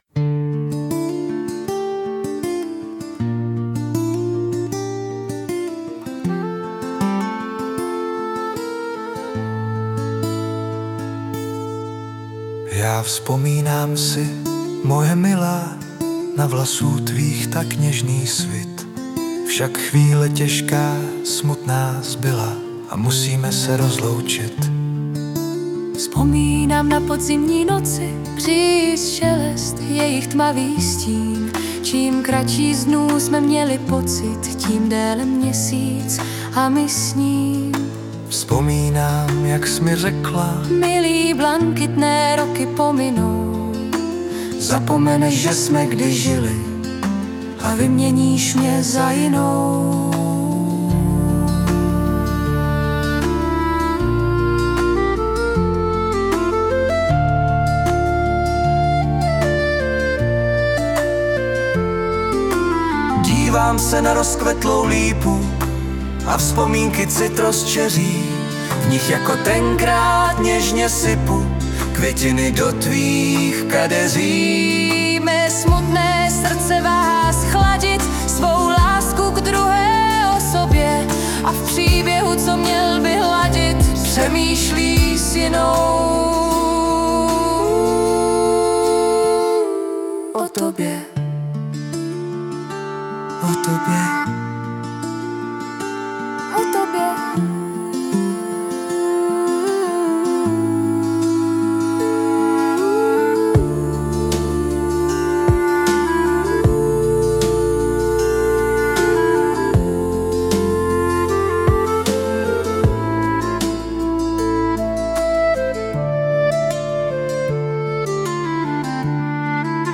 AI zhudebnění tady...